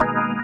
键盘 " 风琴33
描述：记录自DB33Protools Organ。 44khz 16位立体声，无波块。
Tag: 键盘 器官 DB33